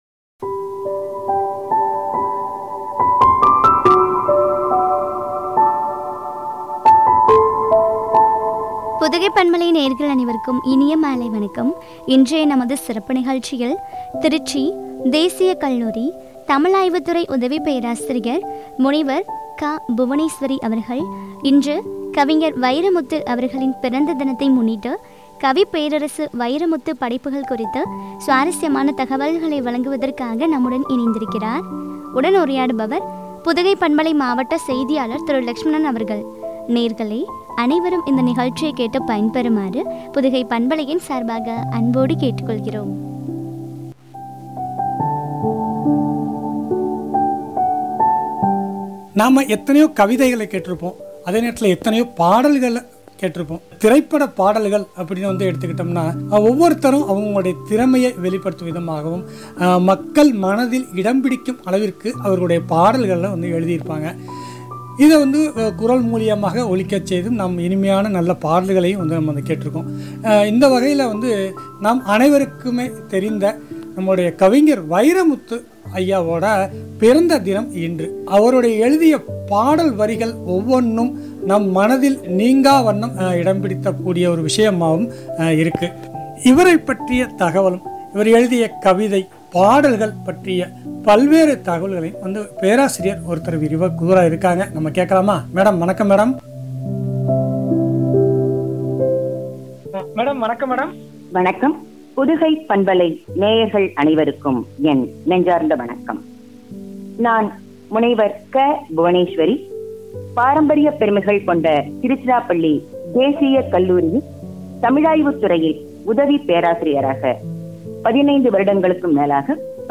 கவிப்பேரரசு வைரமுத்து படைப்புகள்குறித்து வழங்கிய உரையாடல்.